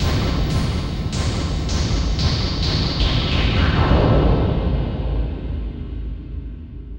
Processed Hits 18.wav